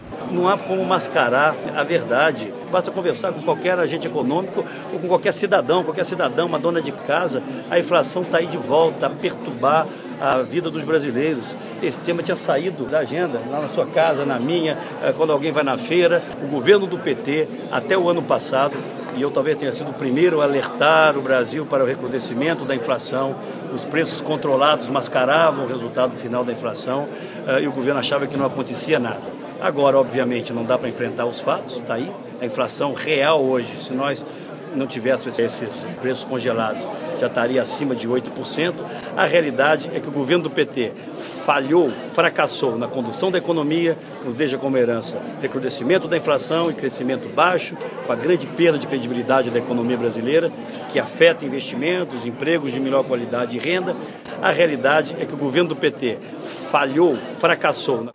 Sonora de Aécio Neves